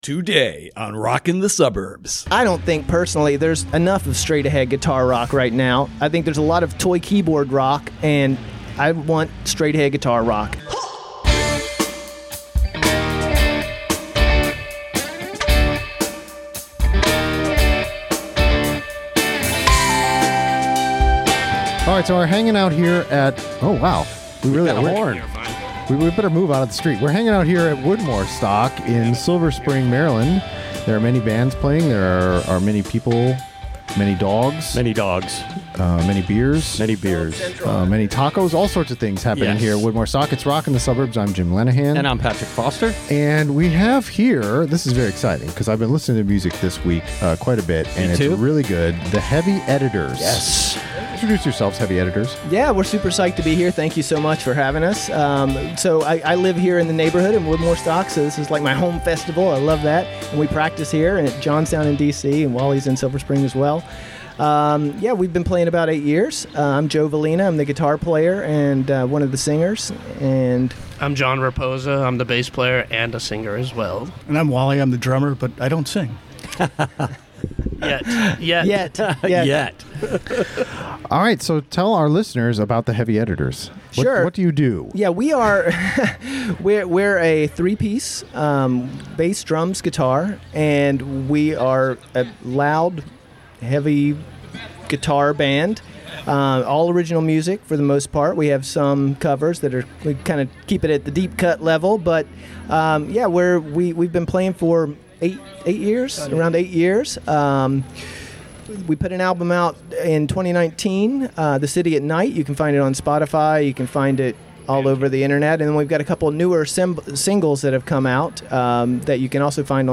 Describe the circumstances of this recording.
Live from Woodmoorstock with the Heavy Editors